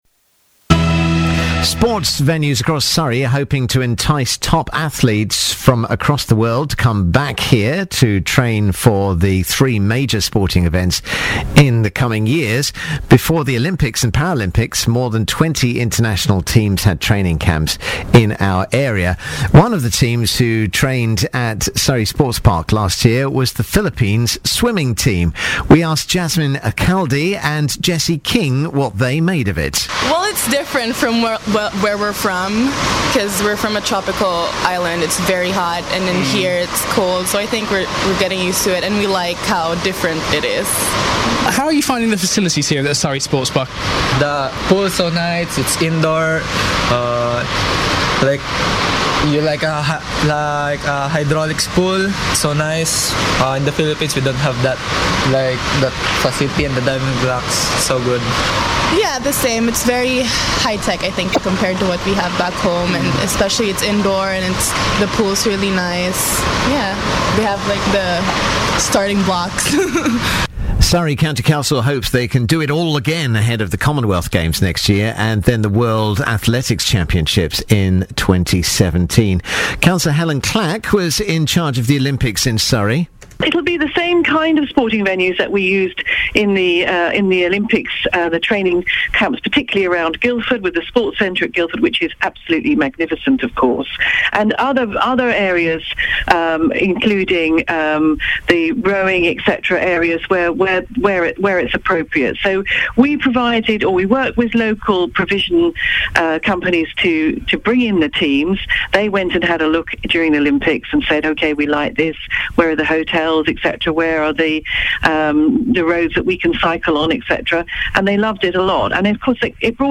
BBC interview on signing up teams to train for major sports events
Cabinet Member for Community Services Helyn Clack was interviewed on BBC Surrey about Surrey County Council’s plans to attract teams to train in the county for major sporting events following the success of the Olympics last summer.